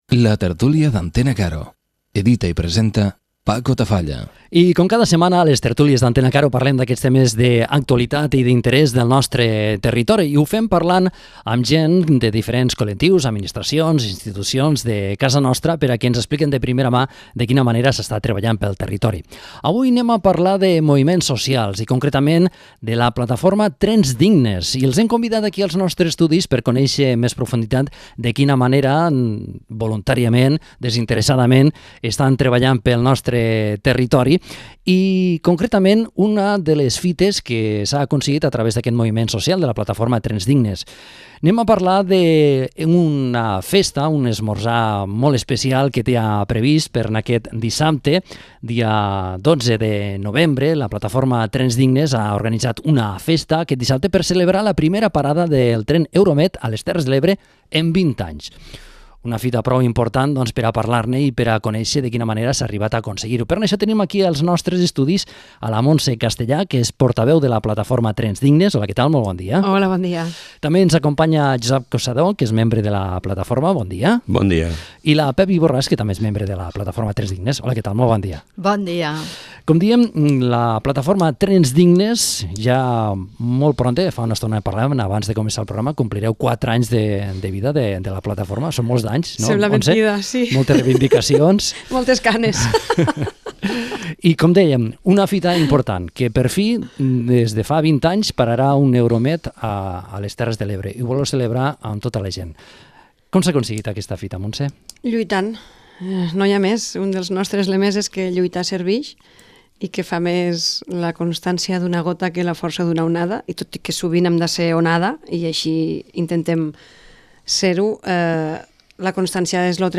La tertúlia d'Antena Caro
Informatiu